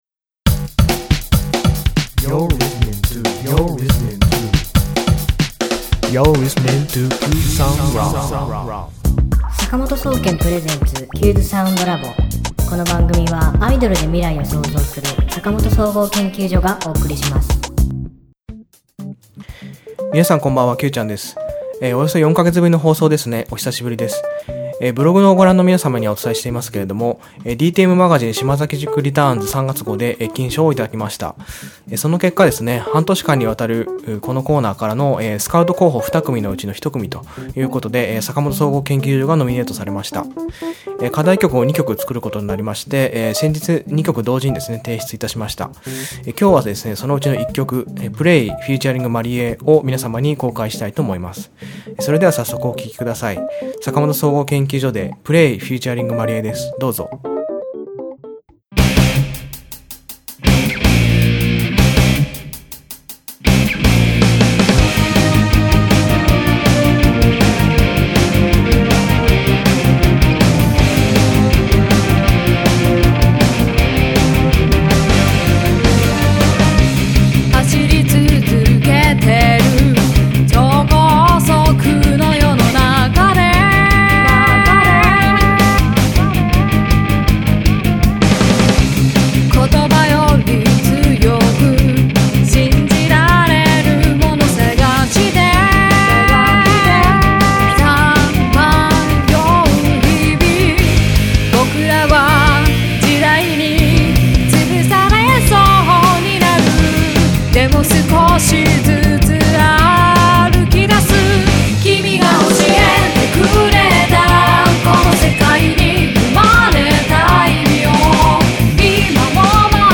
今週のテーマ：課題曲「女性アイドルを意識したロック系の曲」
今週の挿入歌
作詞・作曲・編曲・ギター：坂本総合研究所